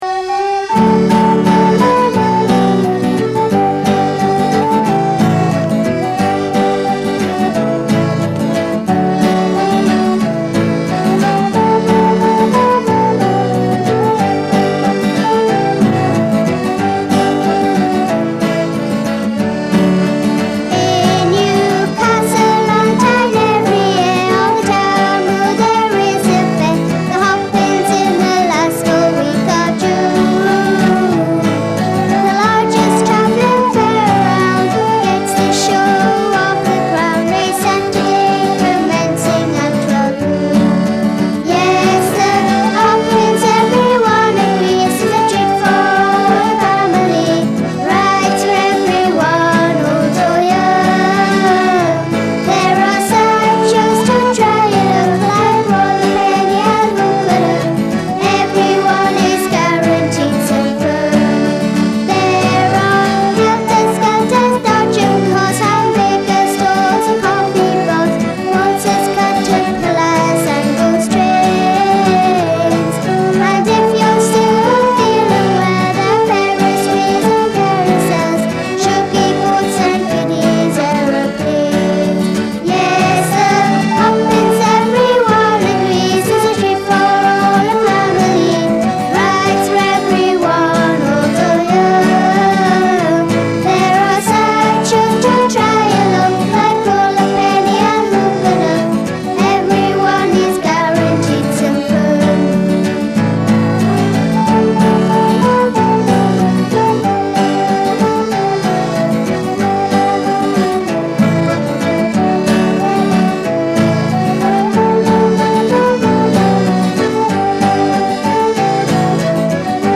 Vocals
Guitar
Recorder
Flute
Piano Accordion.